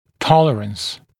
[‘tɔlərəns][‘толэрэнс]допуск, мед. толерантность, переносимость, выносливость